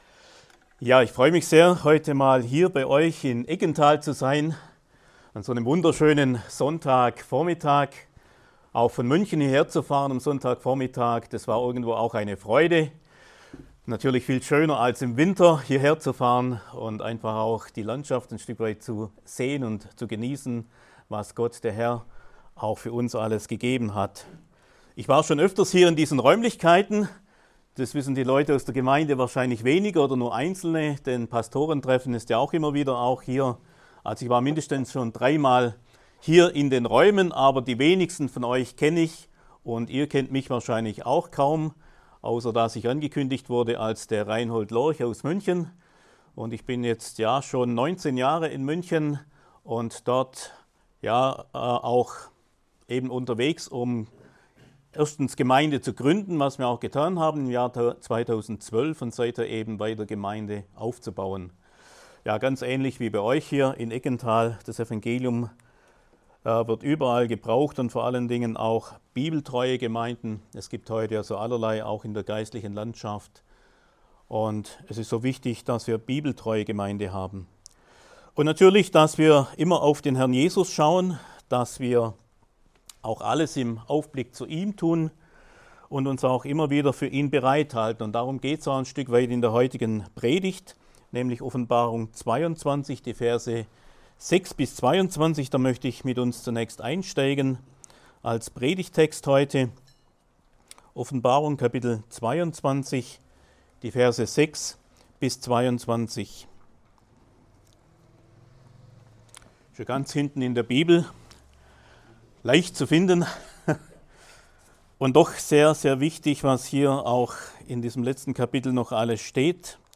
Gottesdienst am 13.10.2019